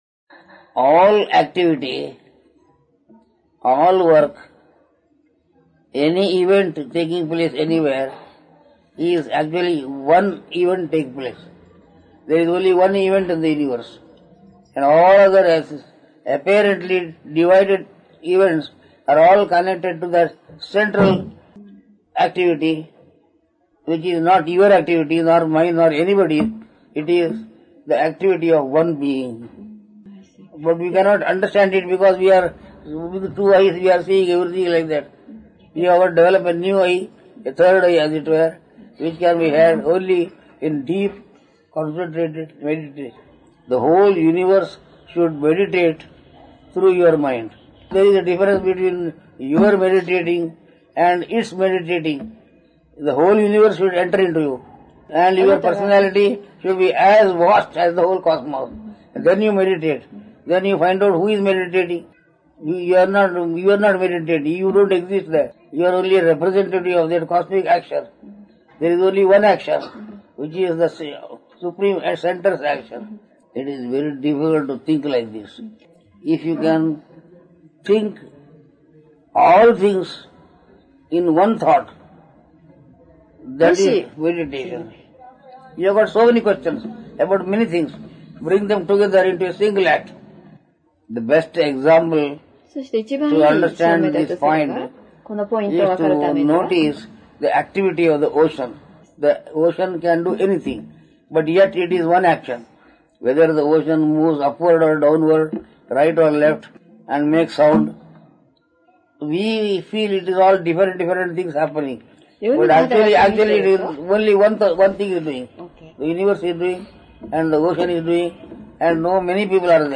One Event in the Universe - Darshan of Swami Krishnananda March 8, 1999.